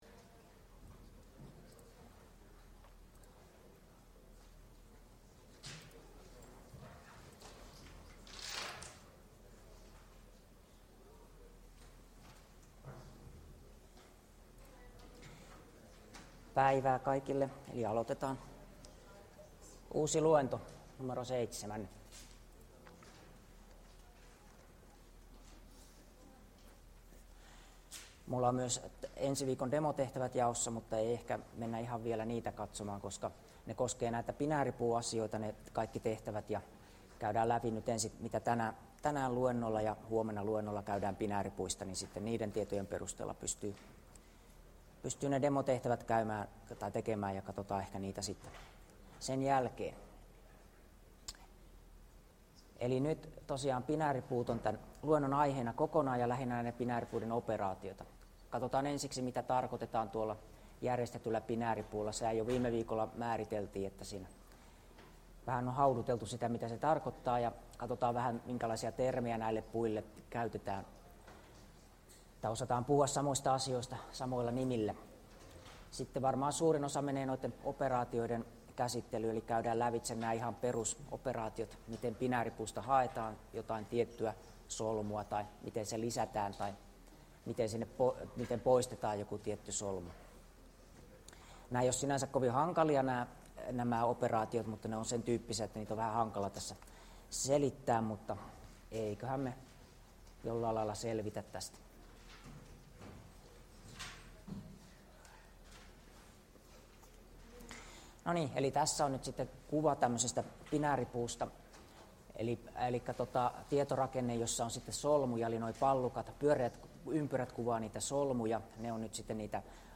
Luento 7 2120eb2ee1e34d6890c718f422fa0991